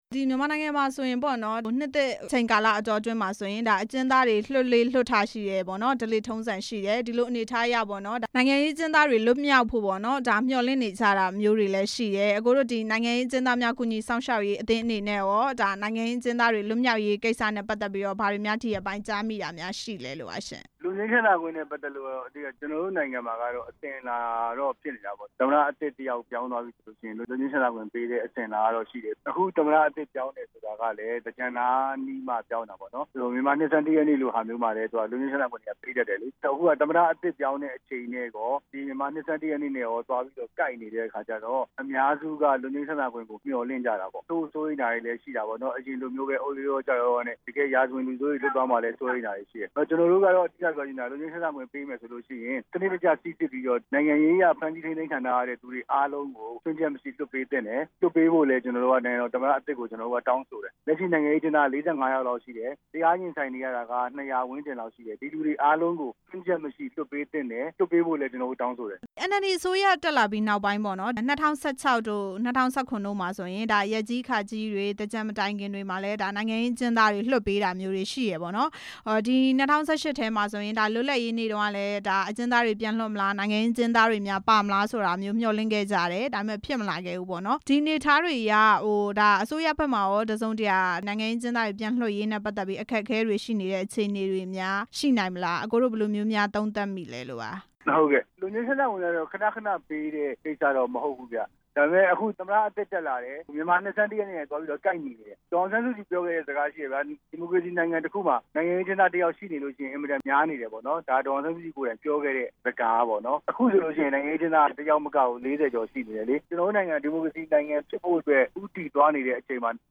နှစ်သစ်မှာ လွတ်ငြိမ်းချမ်းသာခွင့်ပေးဖို့ မျှော်လင့်ချက်အကြောင်း မေးမြန်းချက် — မြန်မာဌာန